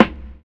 LO FI 9 SD2.wav